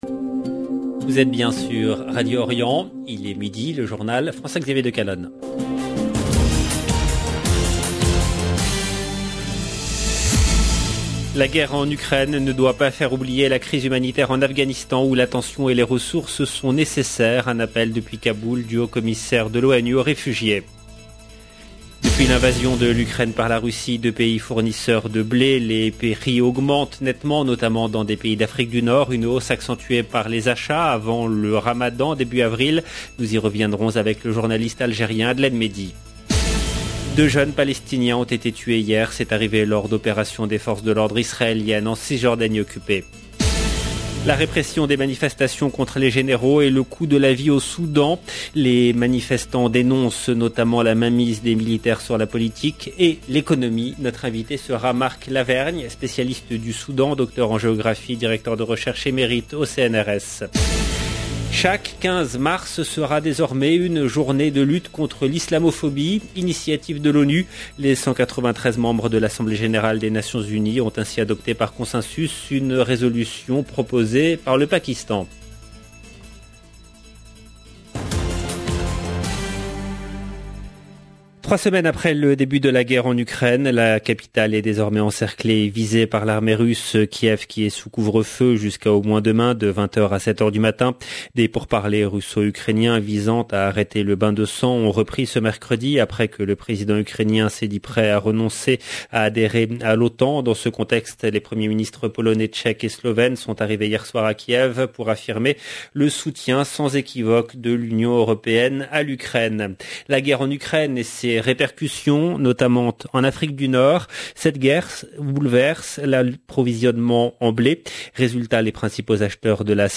Journal présenté par